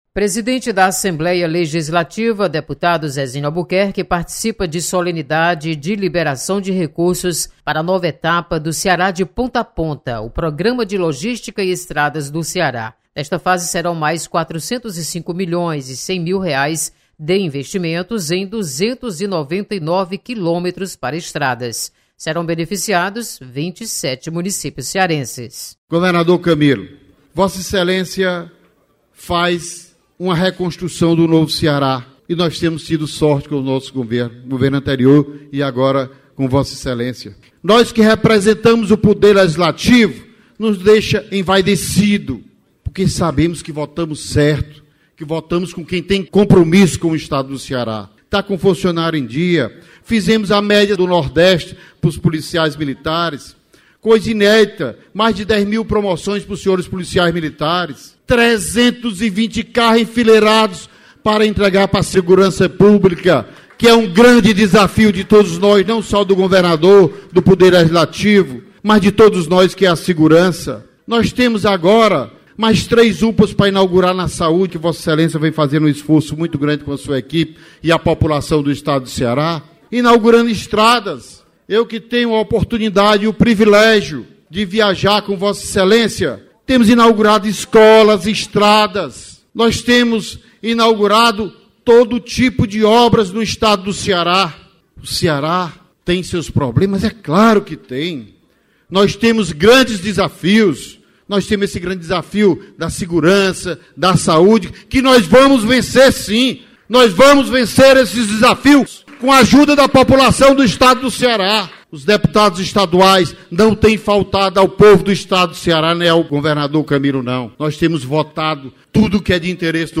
Deputado Zezinho Albuquerque, presidente da Assembleia Legislativa, participa de solenidade do programa Ceará de Ponta a Ponta.